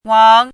chinese-voice - 汉字语音库
wang2.mp3